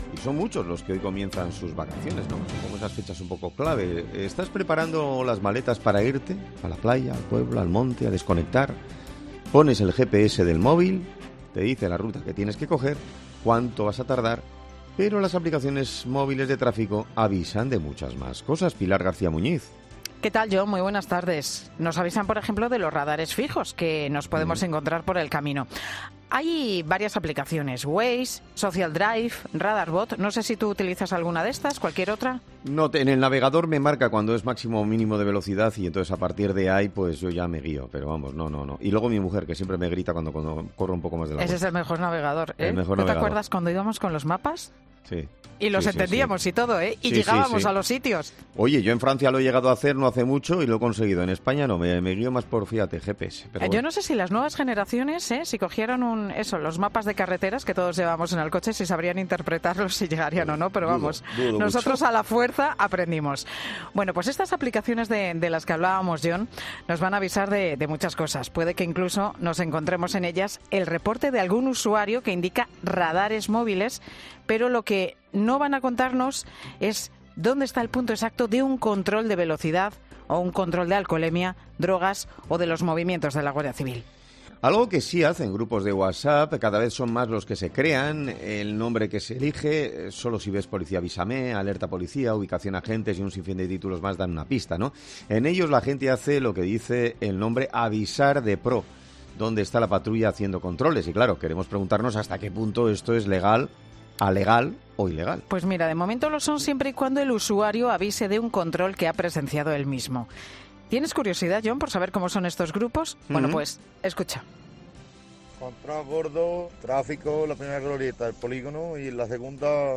Para ello, el fiscal de sala y coordinador de seguridad vial, Luis del Río, responde en "Herrera en COPE" a todas las incertidumbres generadas por esta picaresca práctica.